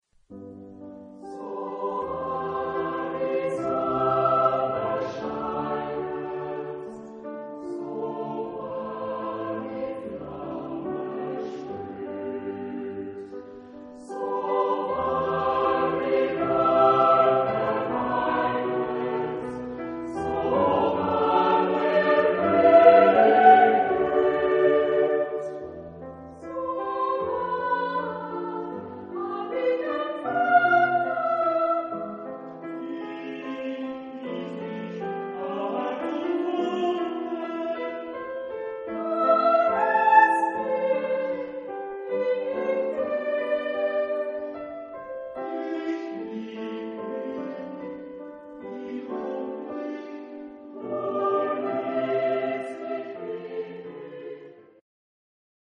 Genre-Style-Form: Secular ; Romantic
Type of Choir: SATB  (4 mixed voices )
Instruments: Violin (3)